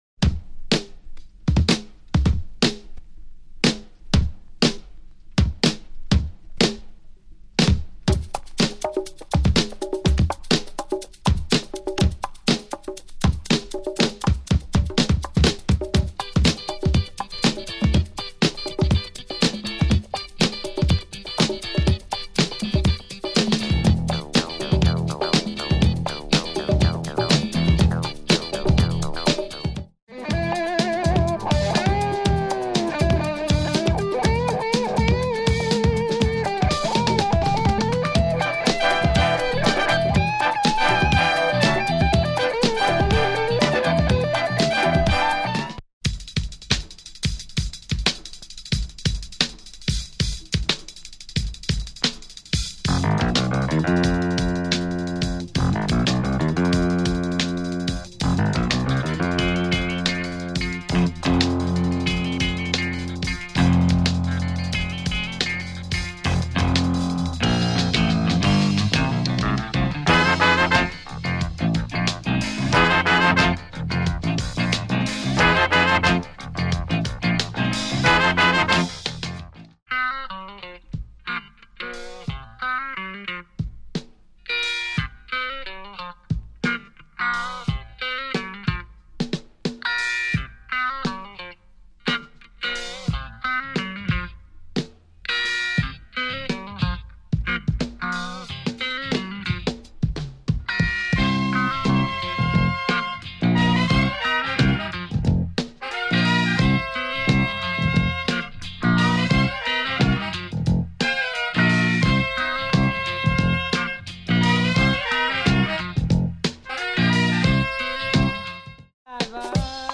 Three great funky cuts on this Hungarian record
the last one has a killer drumbreak intro
groovy intro with dope drumbreaks